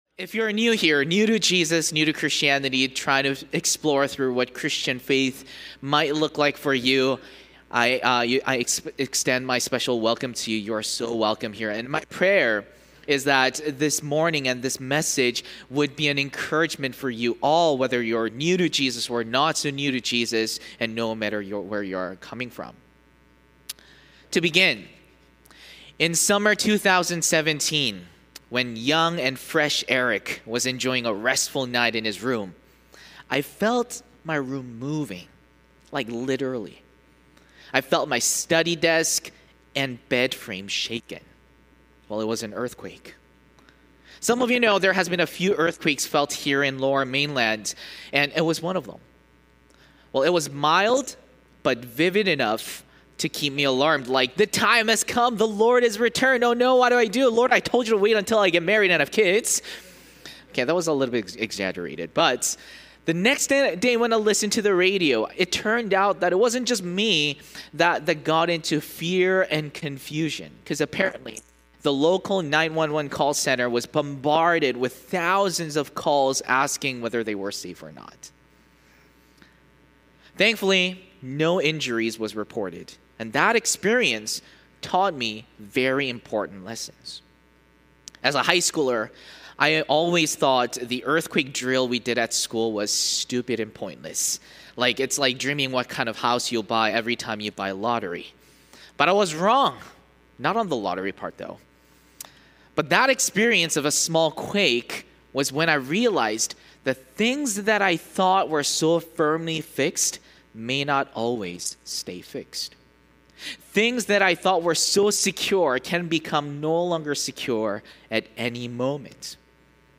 Willowbrook Sermons | North Langley Community Church